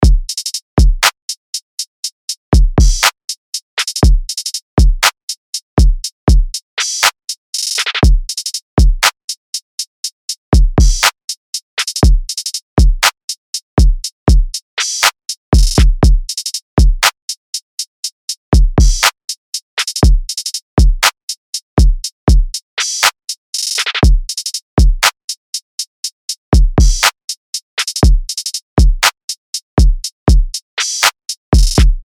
LP 111 – DRUM LOOP – TRAP – 60BPM
LP-111-DRUM-LOOP-TRAP-60BPM.mp3